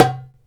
DUMBEK 1A.WAV